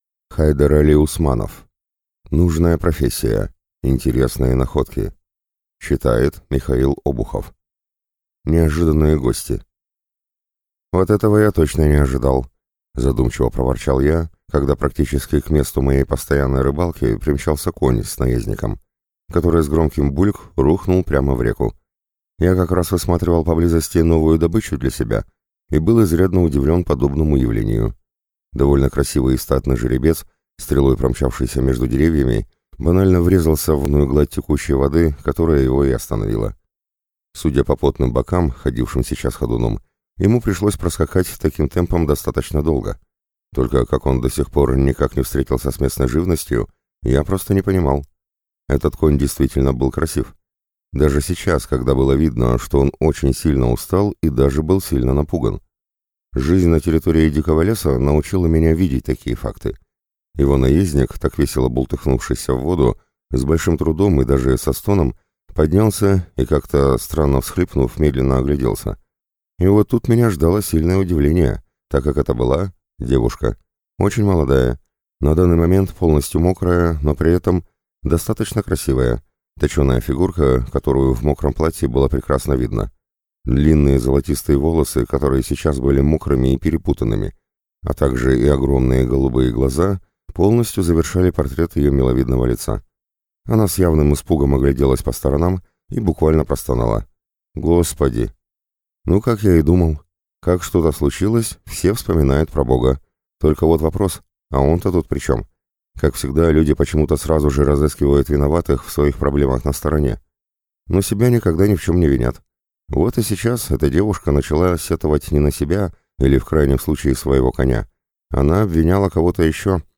Аудиокнига Нужная профессия. Интересные находки | Библиотека аудиокниг